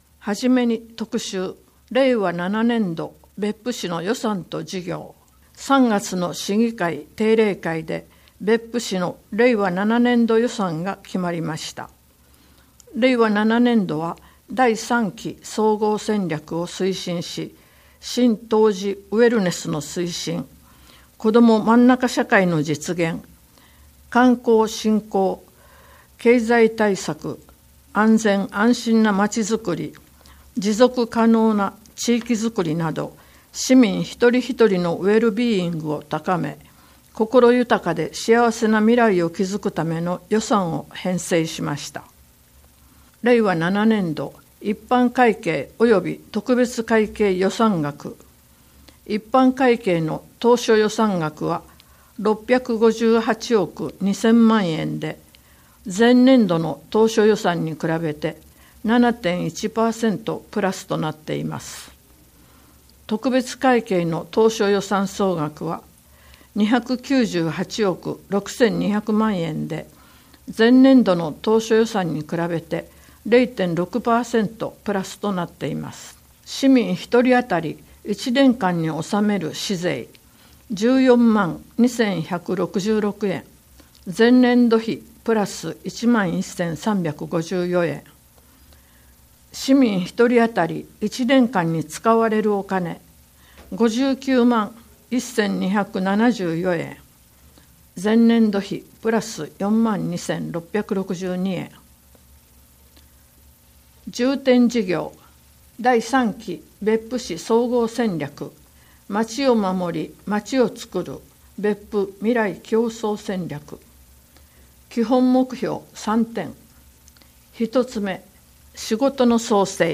毎月市報べっぷの中から、視覚に障がいがある皆さんに特にお知らせしたい記事などを取り上げ、ボランティアグループ「わたげの会」の皆さんに朗読していただいて作られています。